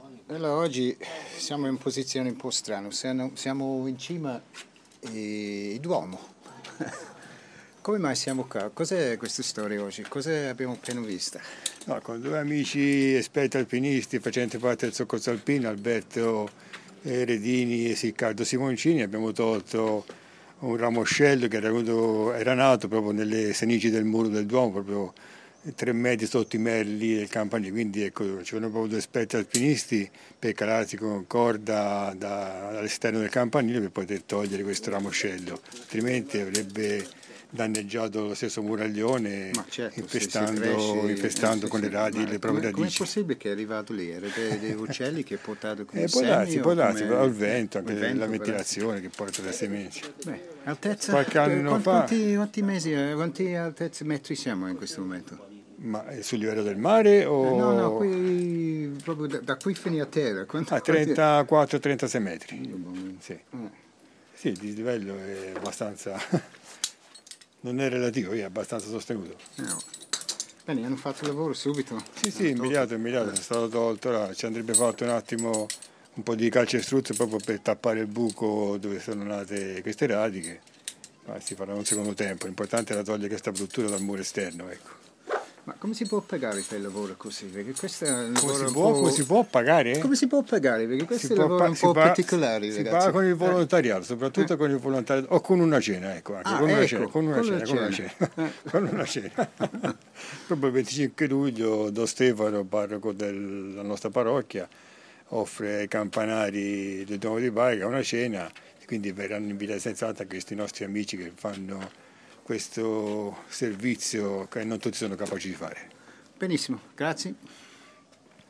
As you can hear in the interview recorded at the top of the Duomo, the two climbers were there to remove plants which had been growing on the side of the tower and were starting to become a possible hazard as the roots dug into the centuries old stone work.